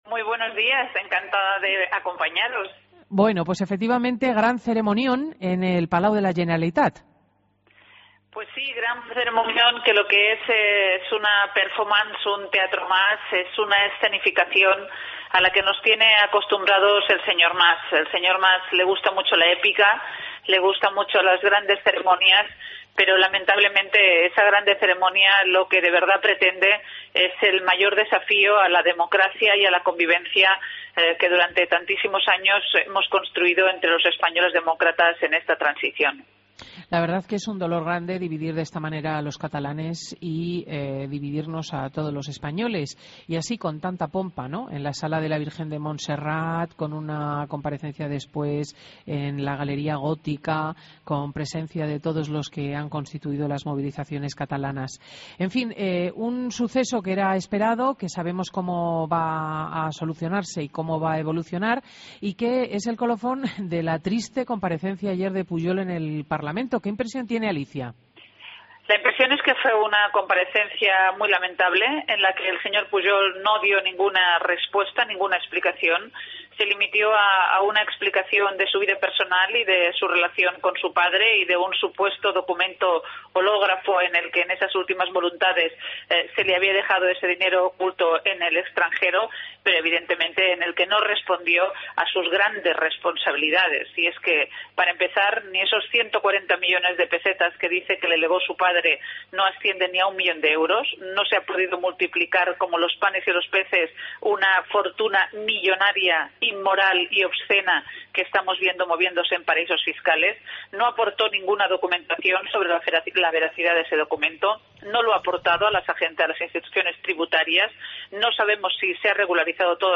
Entrevista a Sánchez Camacho en Fin de Semana COPE